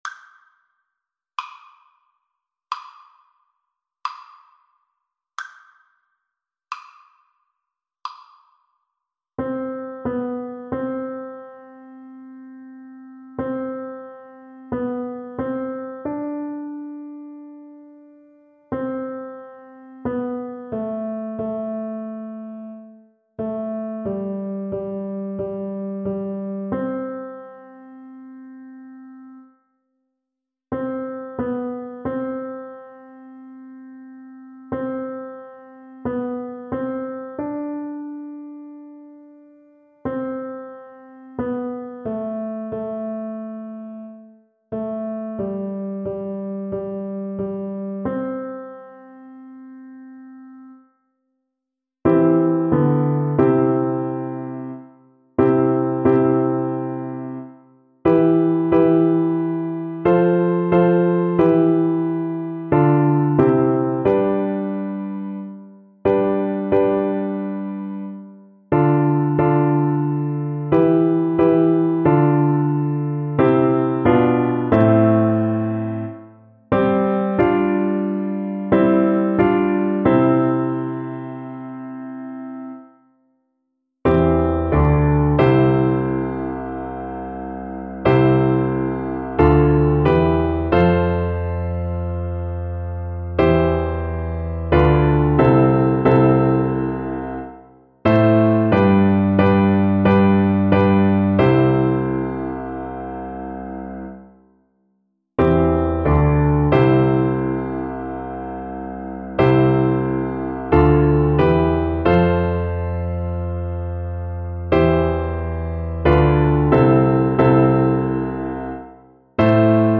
Play (or use space bar on your keyboard) Pause Music Playalong - Piano Accompaniment Playalong Band Accompaniment not yet available transpose reset tempo print settings full screen
Flute
Namibian traditional song, sometimes sung as part of a funeral procession.
C major (Sounding Pitch) (View more C major Music for Flute )
4/4 (View more 4/4 Music)
Slow and expressive =c.60